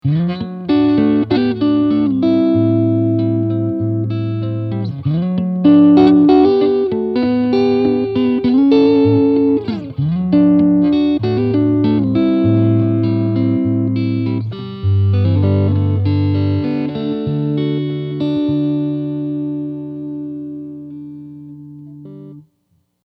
All clips were recorded using my American Deluxe Strat, straight into my VHT Special 6 combo where the speaker resides. Note that I close-miked the amp and had it cranked!
But I did no mastering or EQ.
Clean (Neck pickup)
jetlightning_clean.mp3